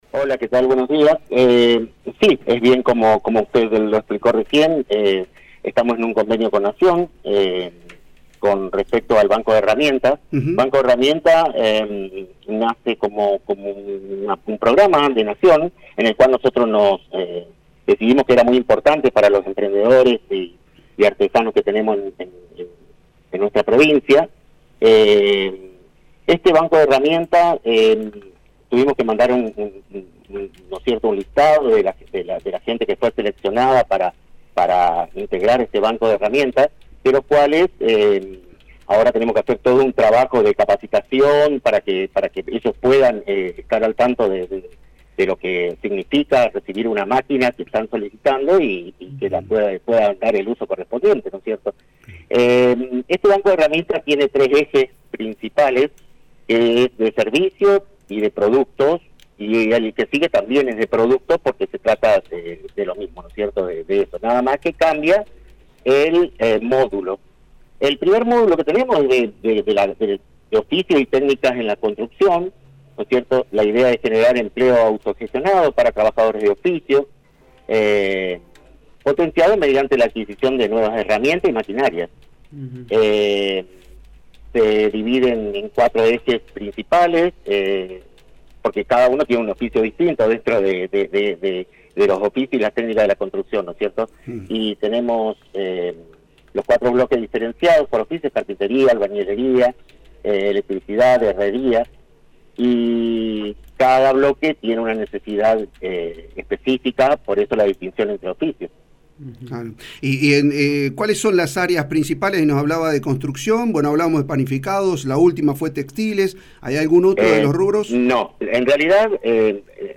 Aldo Ibañez, Director Provincial de Economía Popular, habló en FM 103.1 sobre un  programa de acompañamiento a emprendedores impulsado por el gobierno nacional y coordinado por el gobierno fueguino para  fortalecer a los emprendedores con instancias de capacitación y la adquisición de maquinaria.